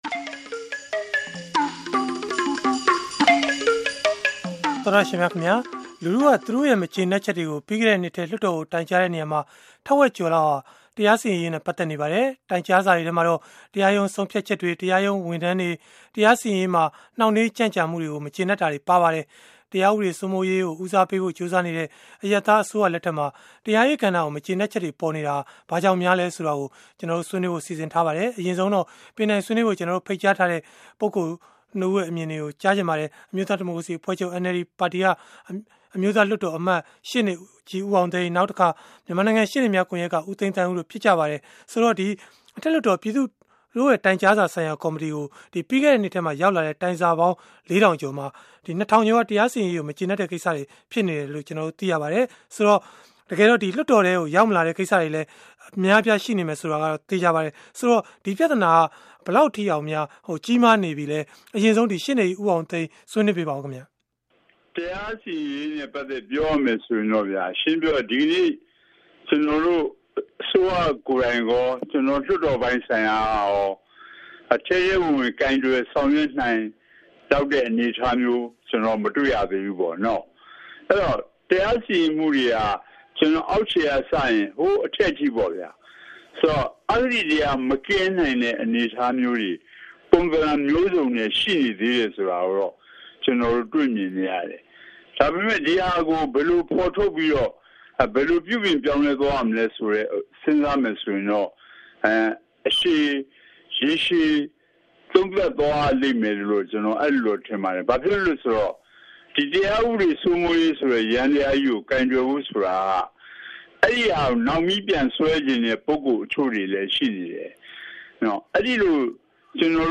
စနေနေ့ည ဗွီအိုအေရဲ့ တိုက်ရိုက်လေလှိုင်း အစီအစဉ်မှာ